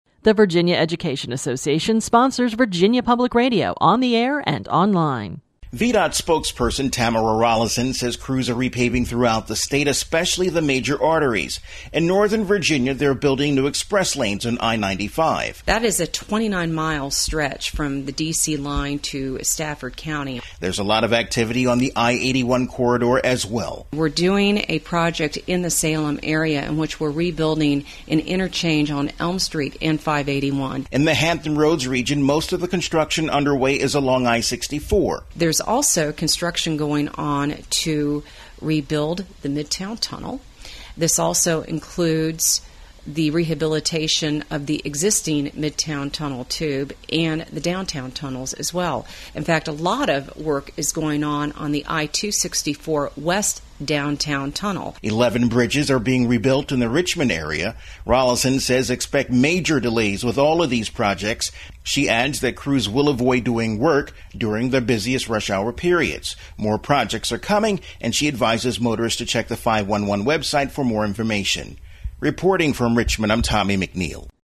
This entry was posted on October 3, 2013, 4:34 pm and is filed under Daily Capitol News Updates.